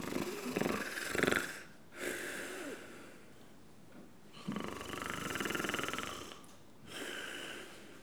Les sons ont été découpés en morceaux exploitables. 2017-04-10 17:58:57 +02:00 1.4 MiB Raw History
ronflement_07.wav